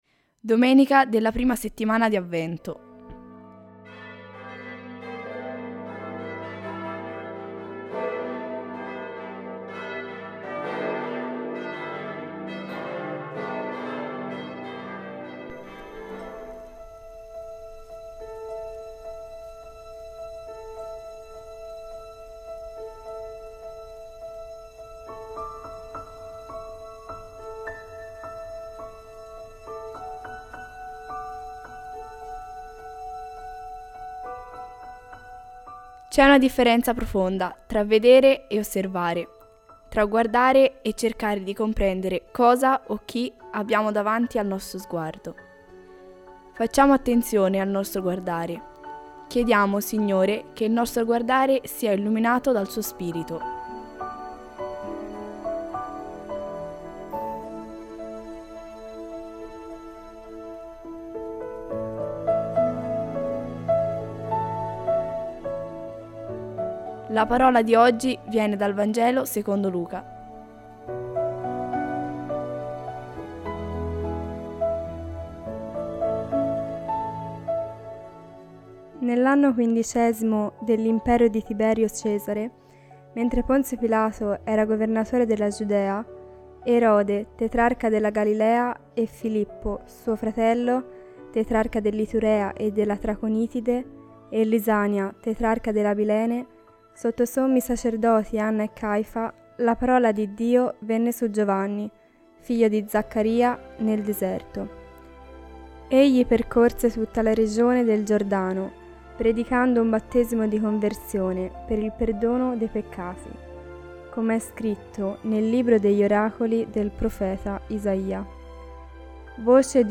Musica tratta dalla colonna sonora di Forrest Gump